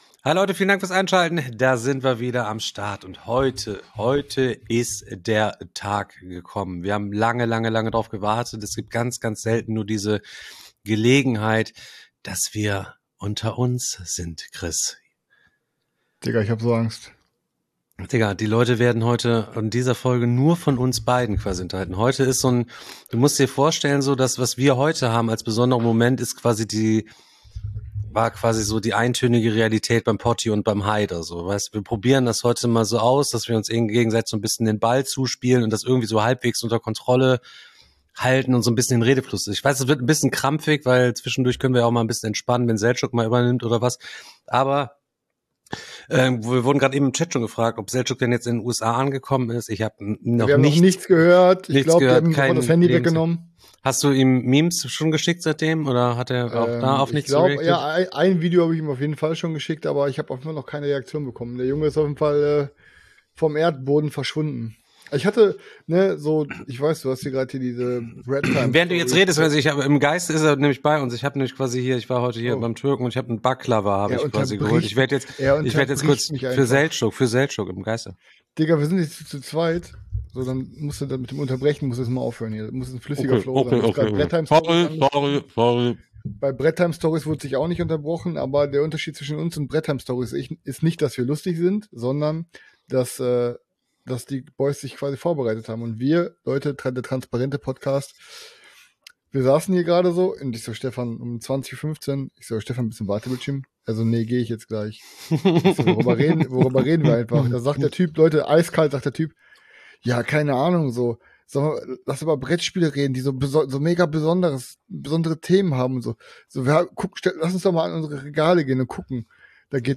1 MOCK DRAFT PART 2 - INTERVIEW MIT COLTS WR MICHAEL PITTMAN JR. | OFFSEASON FOLGE #7 1:30:01